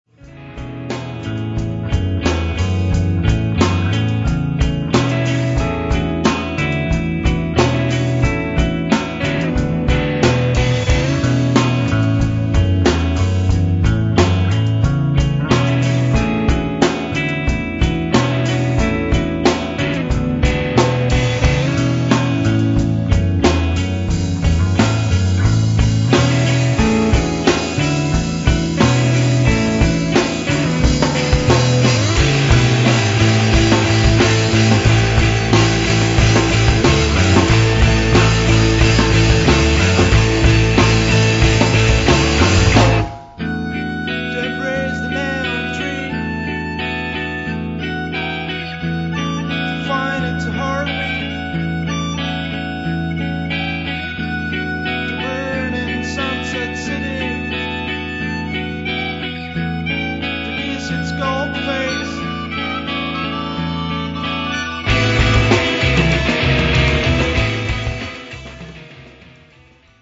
若手ポスト・ロックバンドのホープ